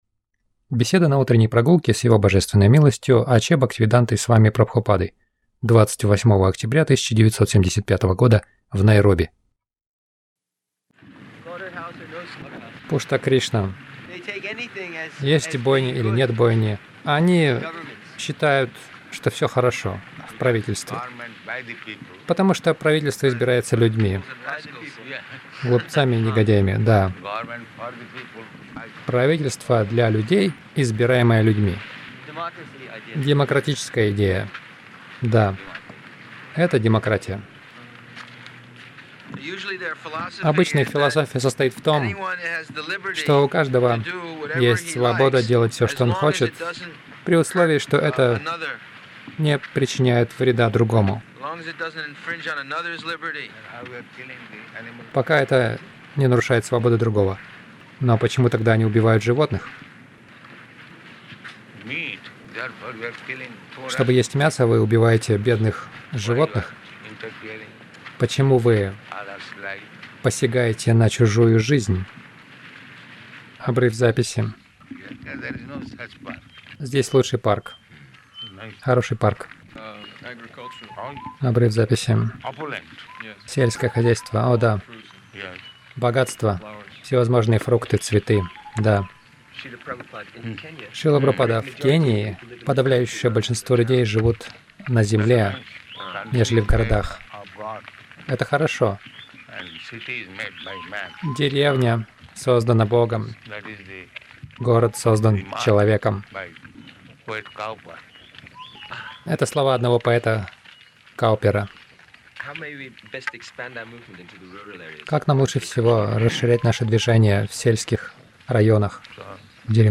Милость Прабхупады Аудиолекции и книги 28.10.1975 Утренние Прогулки | Найроби Утренние прогулки — Как проповедовать в Кали-югу Загрузка...